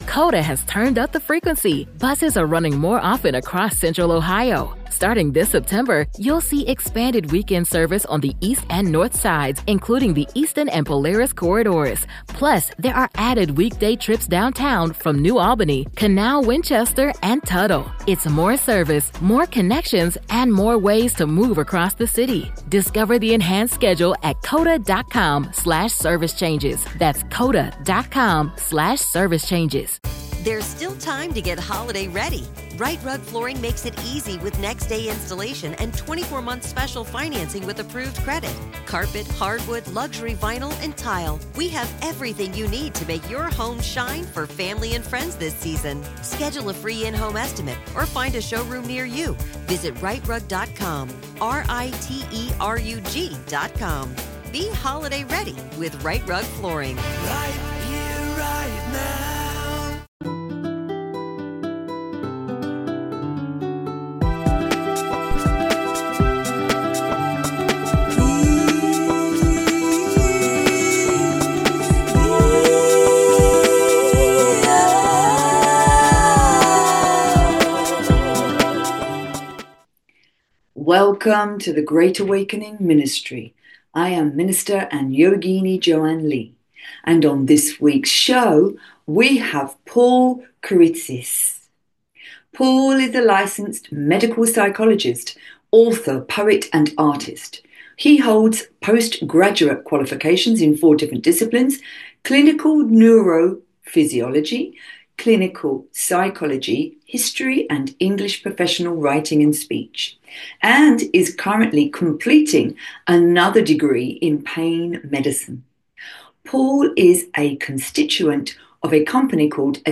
Every show has awakening chats and interviews with incredible people from all around the world; light-workers, way-showers, truth speakers, earth keepers, love embracers, healers and therapists, and all those who are benefiting others and our planet in some way.